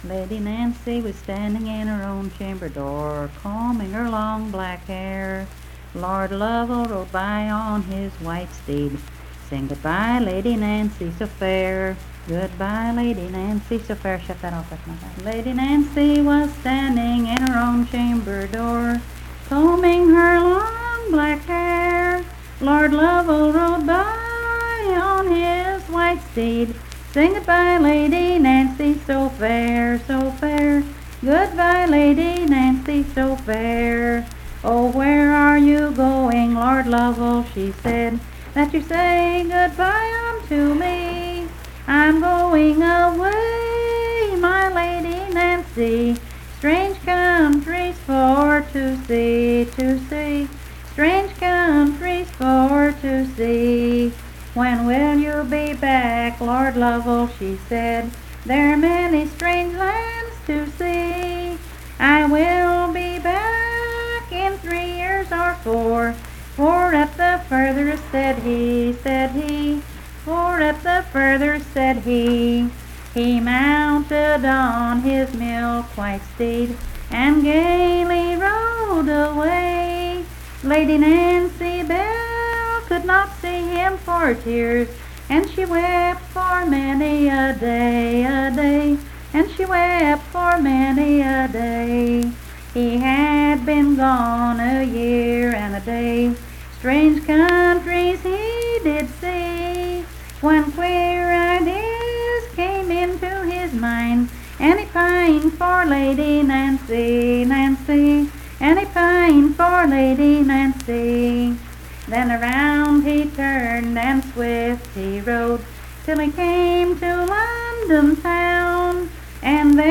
Unaccompanied vocal music
Performed in Coalfax, Marion County, WV.
Voice (sung)